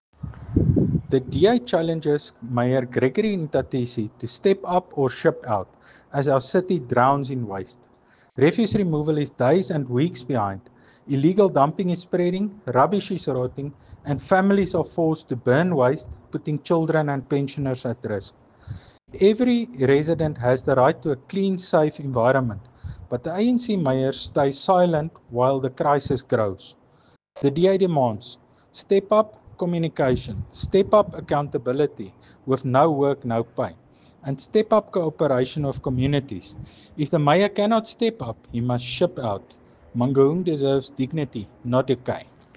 Afrikaans soundbites by Cllr Tjaart van der Walt and Sesotho soundbite by Cllr Kabelo Moreeng.